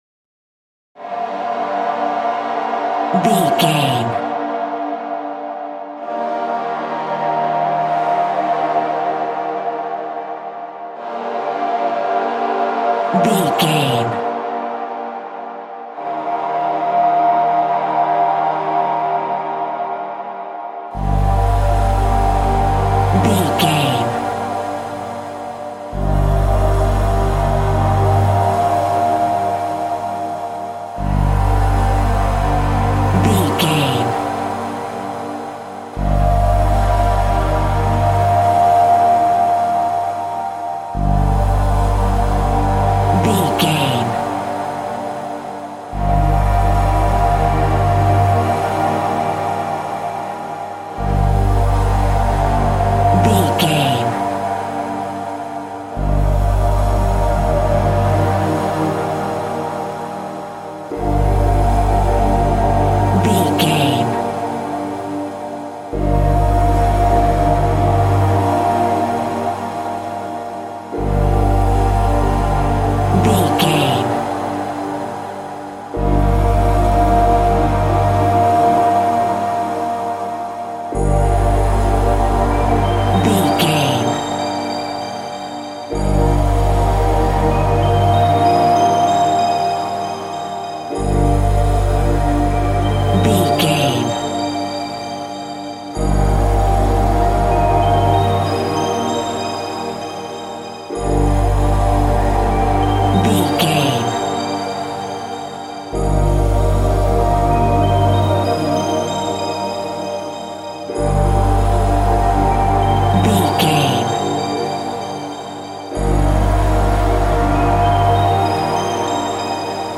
Dark and cold ambient minimalist track
Aeolian/Minor
melancholic
eerie
hypnotic
foreboding
haunting
synthesiser
ethereal